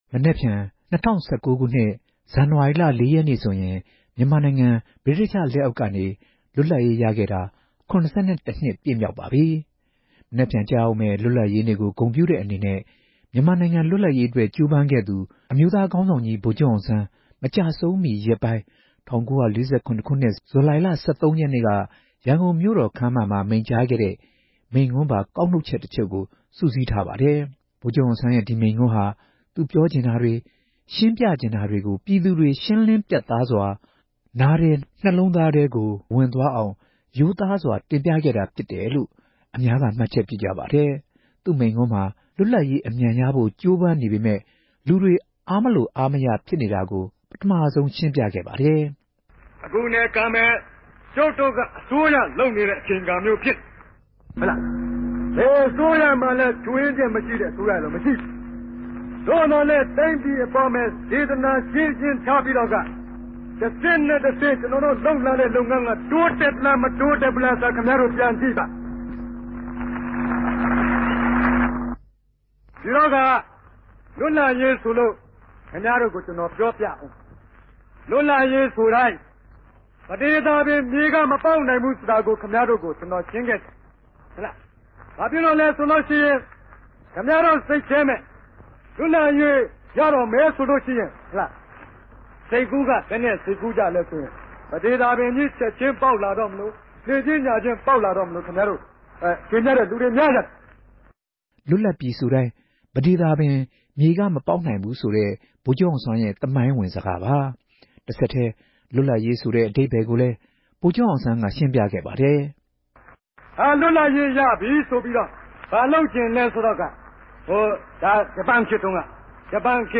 လွတ်လပ်ရေးနေ့နဲ့ ဗိုလ်ချုပ်အောင်ဆန်းမိန့်ခွန်းများ